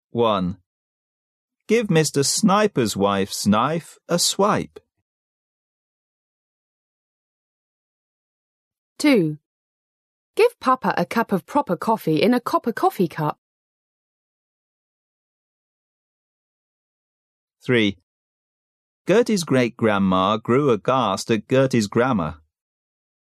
在线英语听力室英语绕口令大全(MP3+中英字幕) 第6期的听力文件下载, 《英语绕口令大全》包含中英文件以及地道纯正的英语音频MP3文件，本栏目中的英语绕口令，对于很多中国人来说，都是比较有难度的。